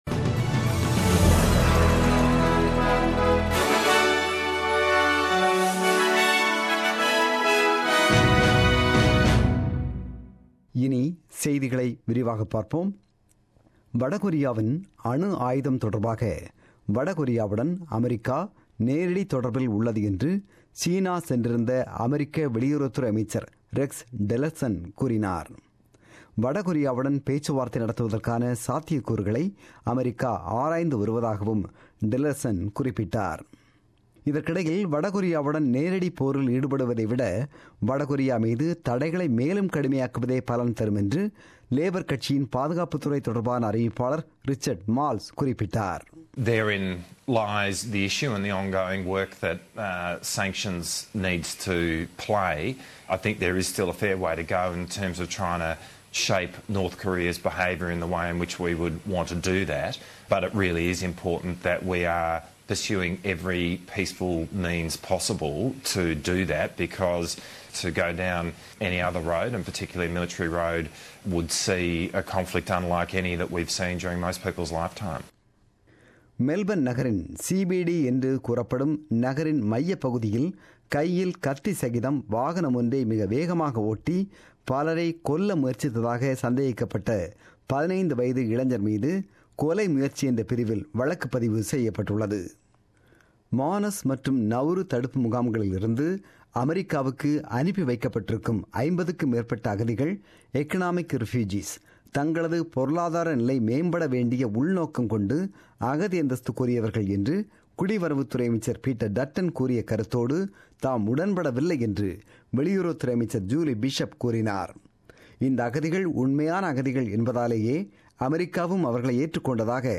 The news bulletin broadcasted on 01 October 2017 at 8pm.